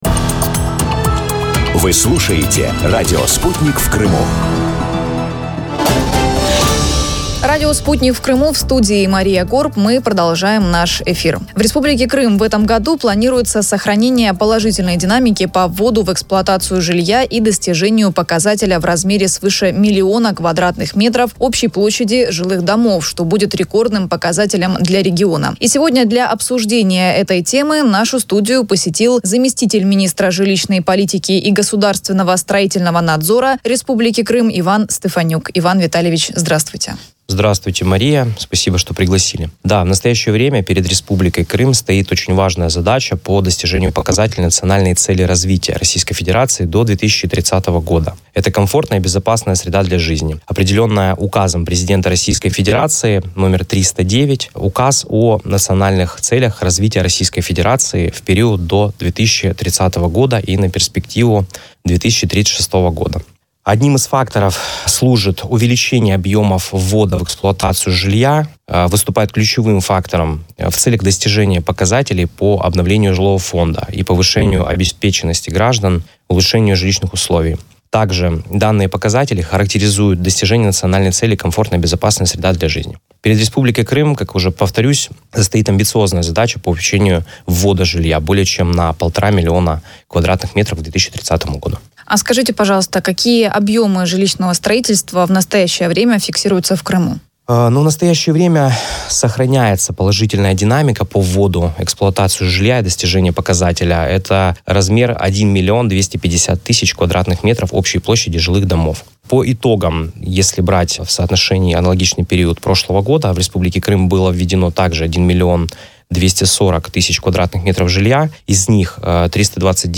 Иван Стефанюк рассказал в прямом эфире радио «Спутник в Крыму» о целях и стратегических задачах в сфере жилищного строительства в Крыму
Заместитель министра жилищной политики и государственного строительного надзора Республики Крым Иван Стефанюк принял участие в эфире на радио « Спутник в Крыму».